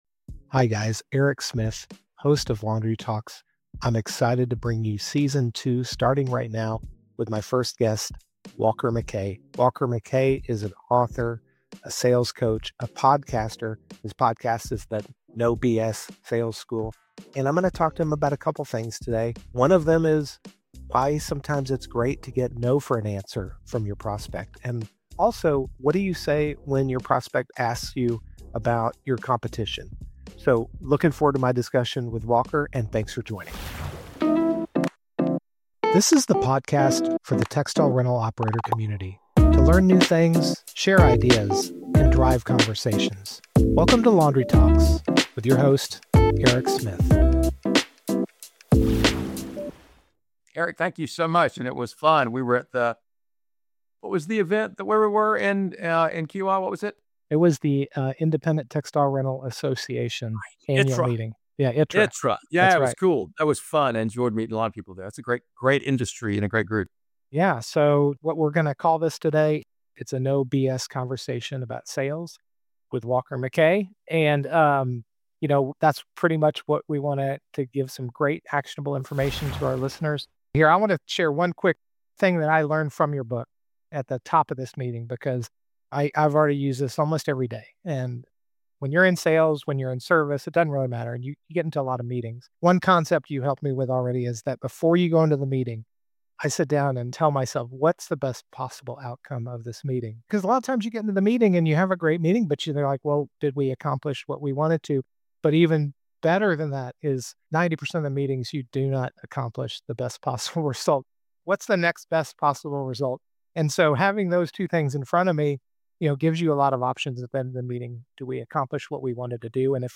No BS Sales Conversation